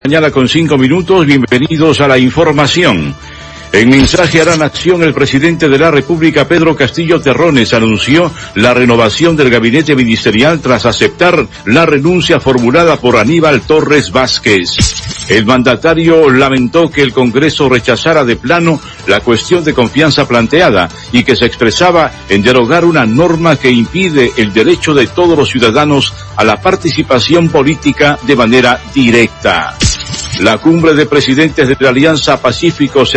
En mensaje a la Nación, el presidente Pedro Castillo anunció la renovación del Gabinete Ministerial tras aceptar la renuncia formulada por Aníbal Torres Vásquez. El mandatario lamentó que el Congreso rechazara de plano la cuestión de confianza planteada y que se expresaba en derogar una norma que impide el derecho a todos los ciudadanos a la participación política de manera directa.